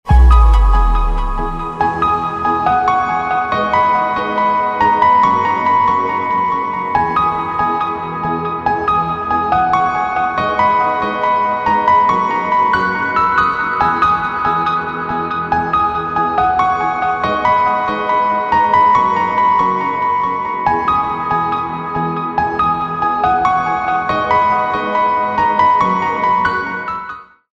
رینگتون پرتحرک و زیبای
(برداشتی آزاد از موسیقی های بی کلام خارجی)